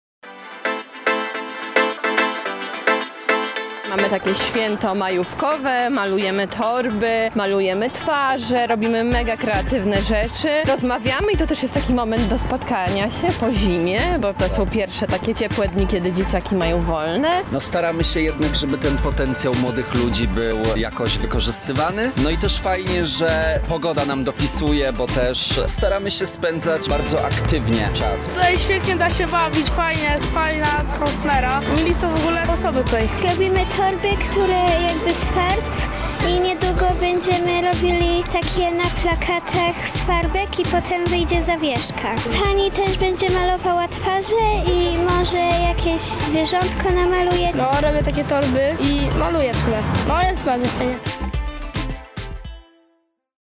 O wrażenia zapytaliśmy również uczestników wydarzenia.